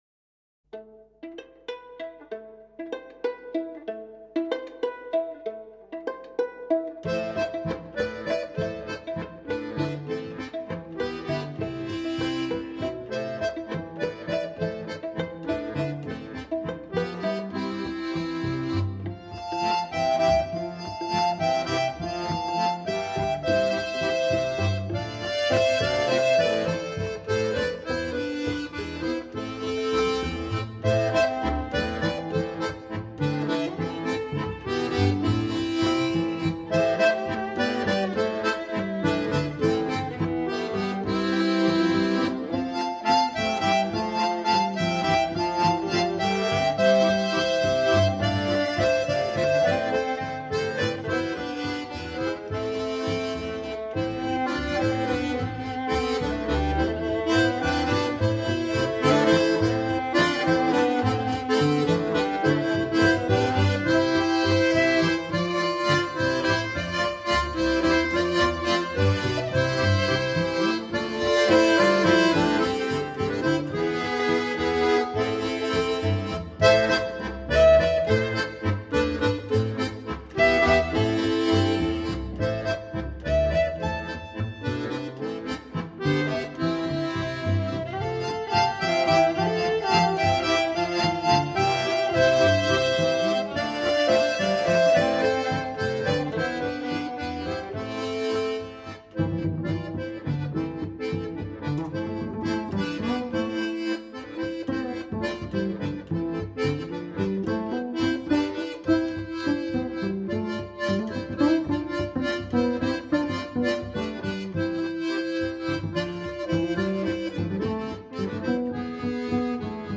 • Je fonctionne un peu comme un harmonica.
• Je suis un instrument à vent qui anime souvent les fêtes !